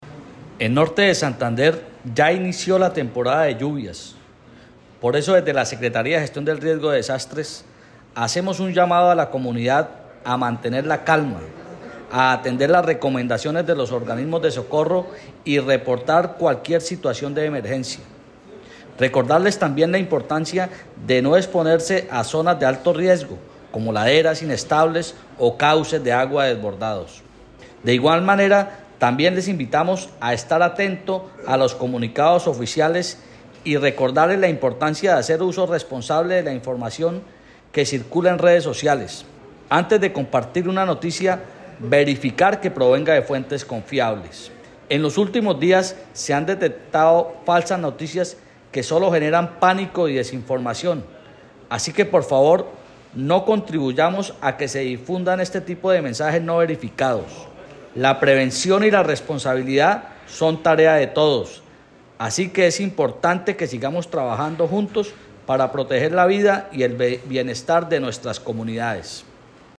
Audio-de-William-Vera-secretario-de-Riesgos.mp3